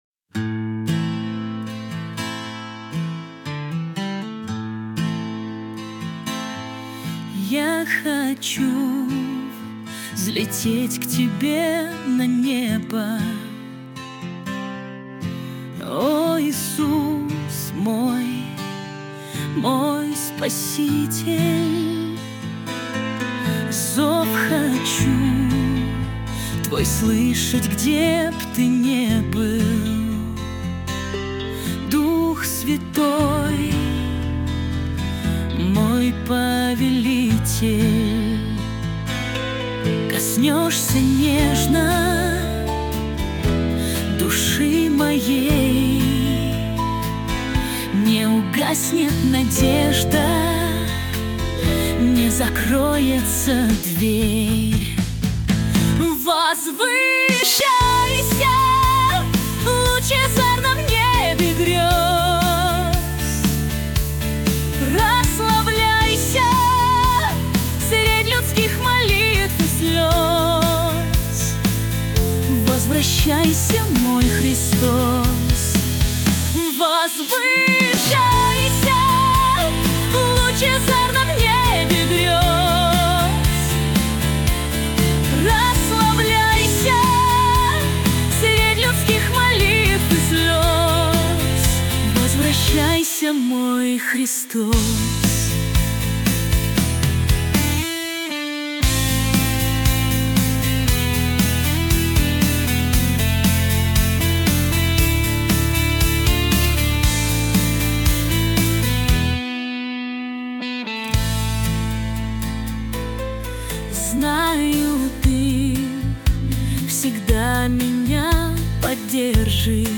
200 просмотров 1318 прослушиваний 64 скачивания BPM: 117